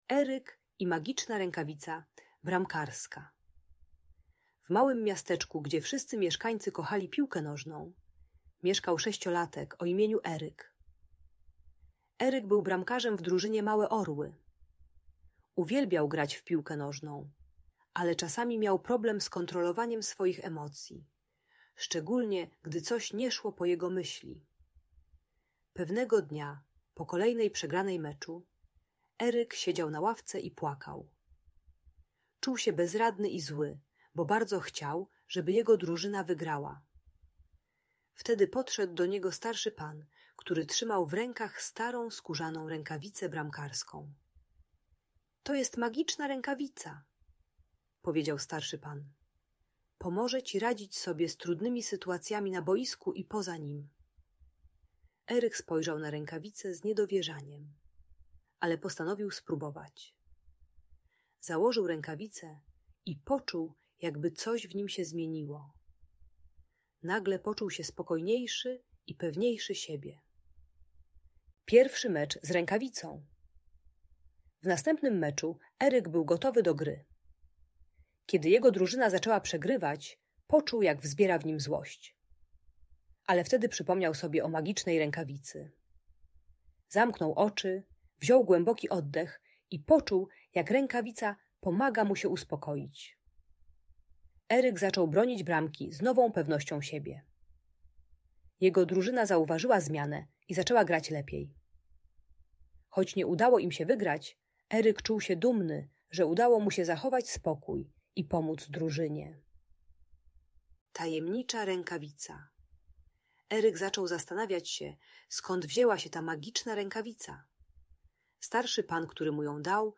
Historia Eryka i Magicznej Rękawicy - Audiobajka